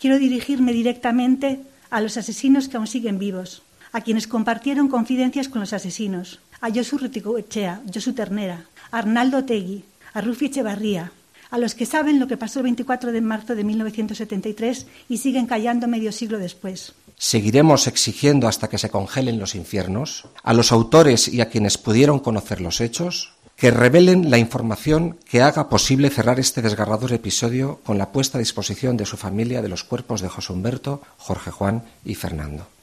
Clamor al que se han sumado las instituciones en un emotivo homenaje celebrado en el Memorial de las Víctimas de Terrorismo de Vitoria y organizado junto a Gogora
y Denis Itxaso, delegado del Gobierno en Euskadi